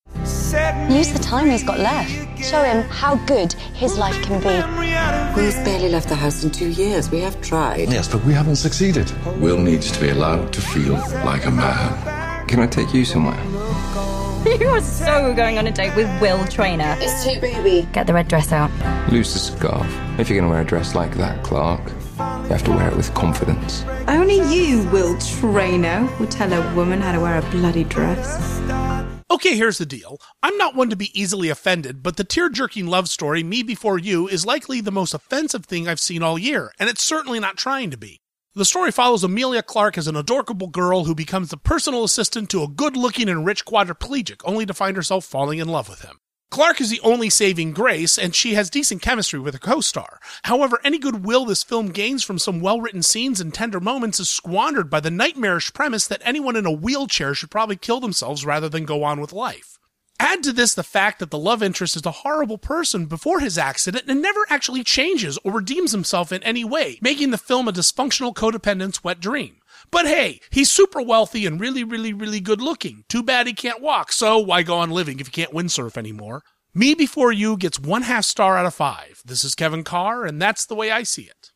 ‘Me Before You’ Radio Review